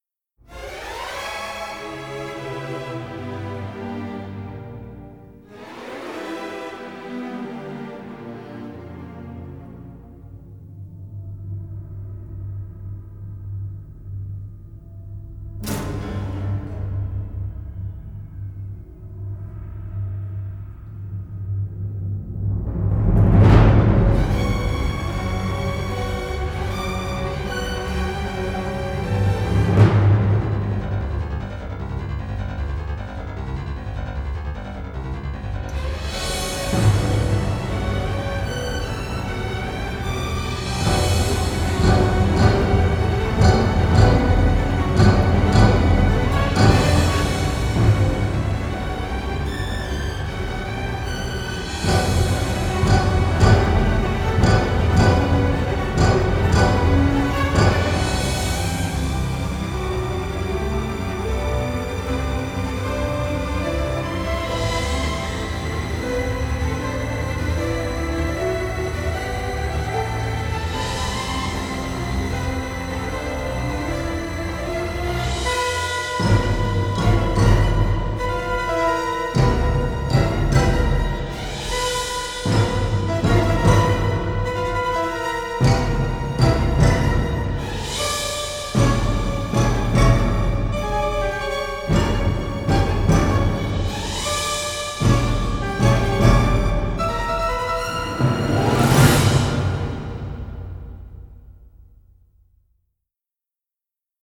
dark thriller score
aggressive thriller elements with edgy character
original stereo session mixes